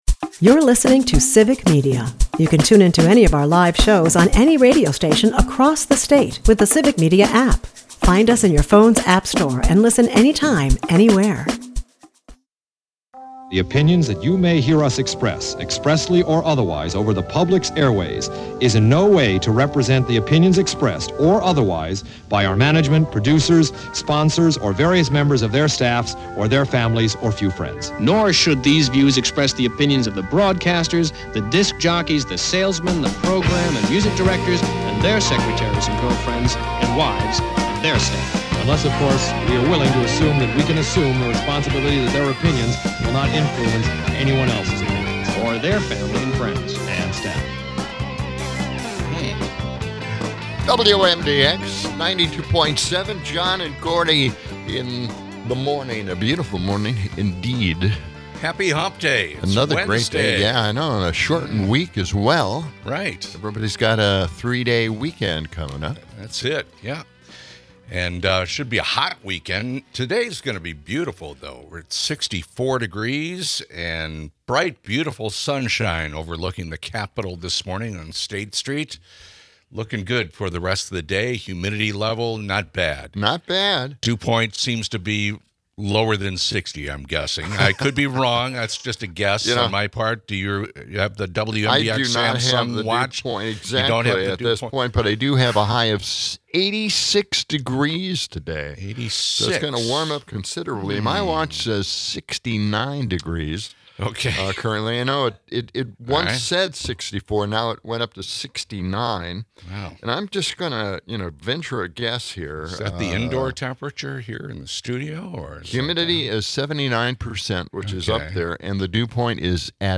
Friendly banter ensues as the guys can't stop talking about the alligator Alcatraz border debate!